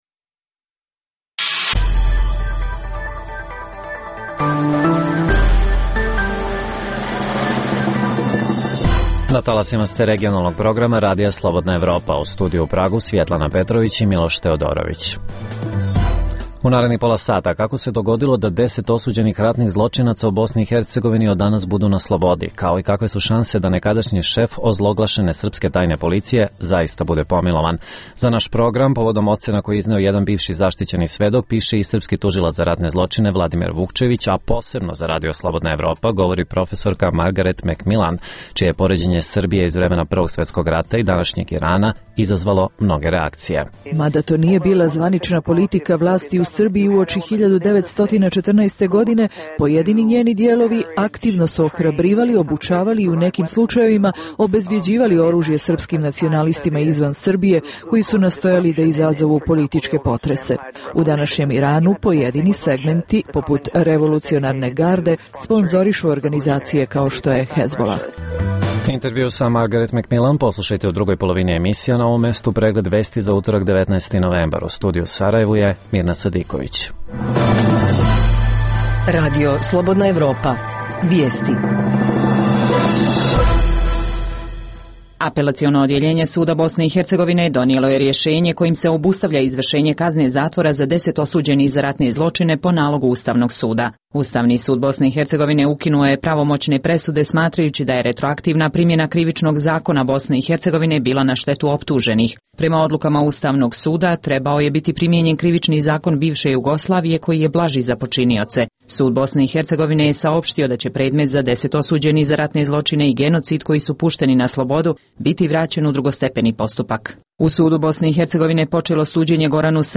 - Intervju: Margaret Mekmilan – Zašto Srbija nekada, podseća na Iran danas.